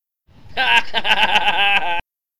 laugh 2